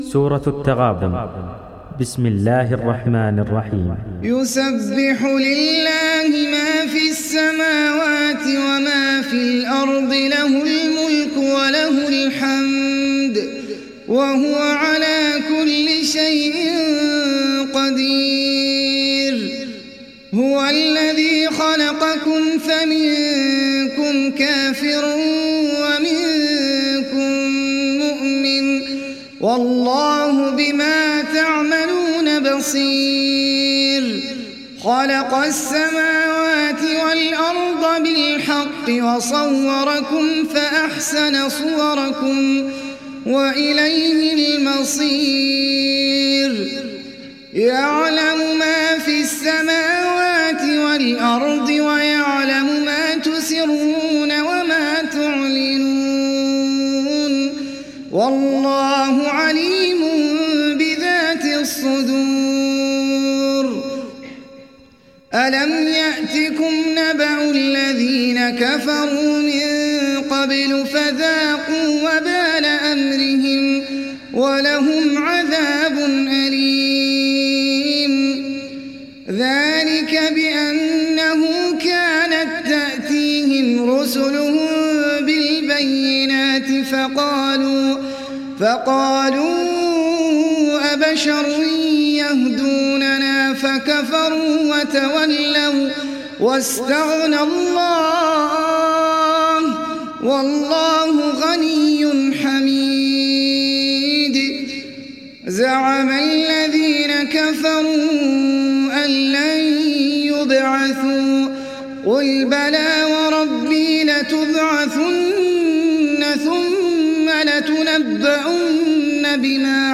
دانلود سوره التغابن mp3 أحمد العجمي روایت حفص از عاصم, قرآن را دانلود کنید و گوش کن mp3 ، لینک مستقیم کامل